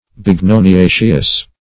Bignoniaceous \Big*no`ni*a"ceous\, a. (Bot.)